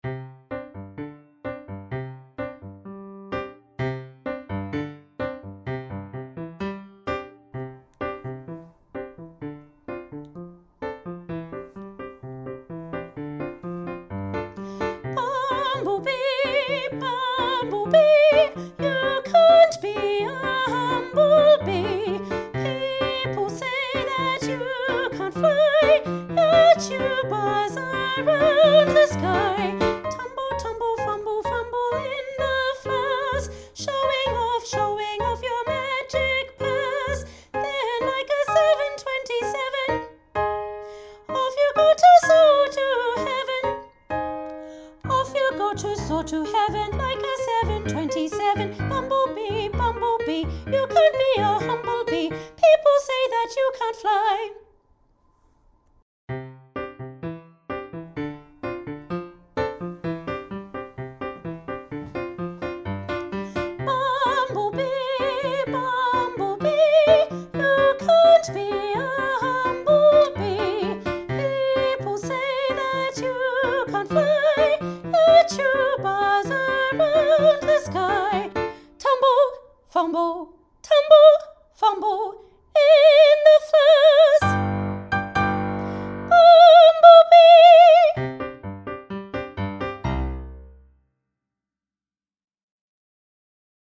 The Bumblebee, Soprano Upper Part Jnr
The-Bumblebee-Soprano-upper-part_Jnr.mp3